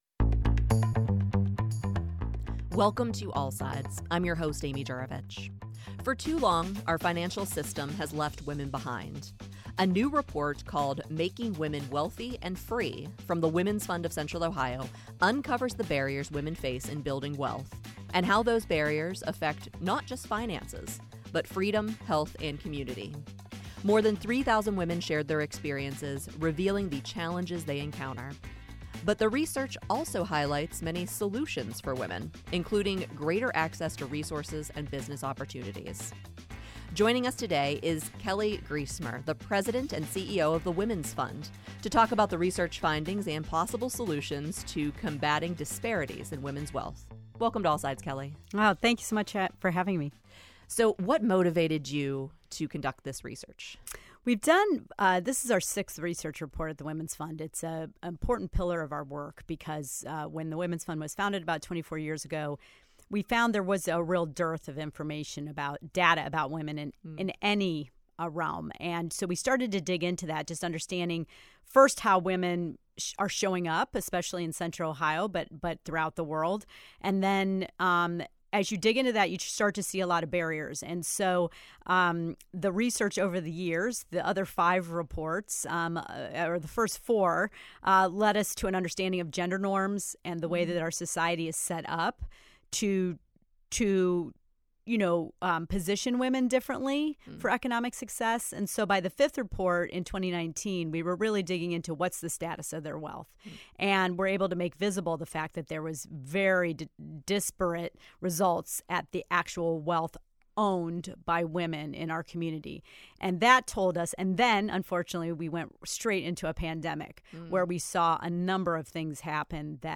The future of women's wealth. Weekly Reporter Roundtable.